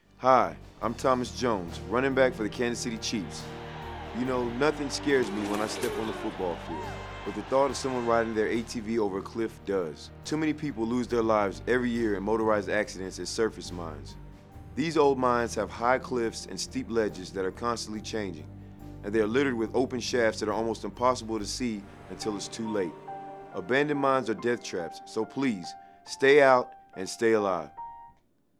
Thomas Jones of the Kansas City Chiefs prepared PSAs for the US Department of Labor